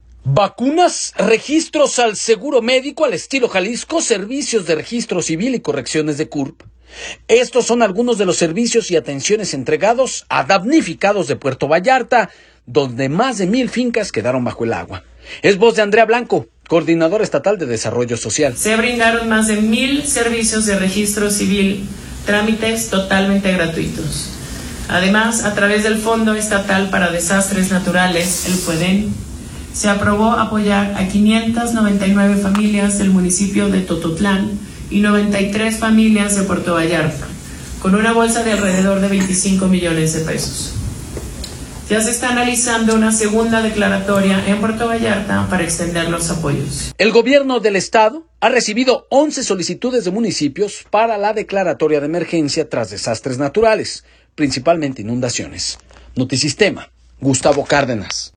Vacunas, registros al seguro médico al estilo Jalisco, servicios de registro civil y correcciones de CURP, estos son algunos de los servicios y atenciones entregados a los damnificados de Puerto Vallarta, donde más de mil fincas quedaron bajo el agua, es voz de Andrea Blanco Coordinadora Estatal de Desarrollo Social.